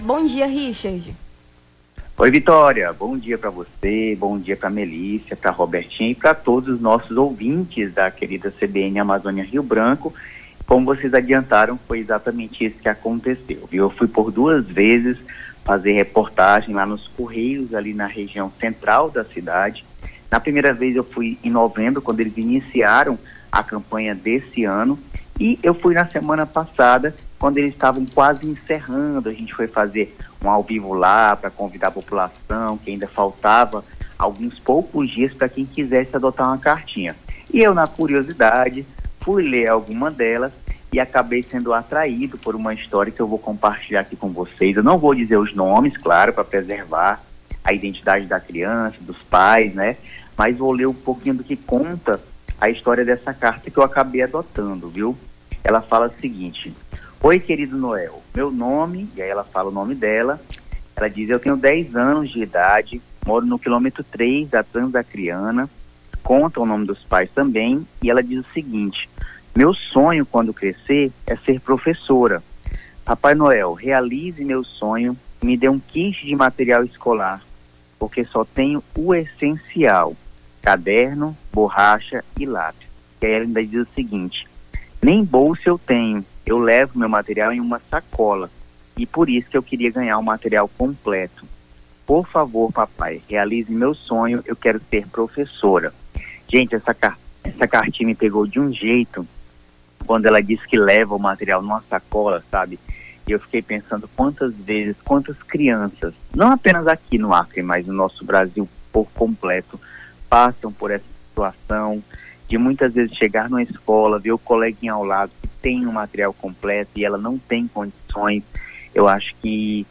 a apresentadora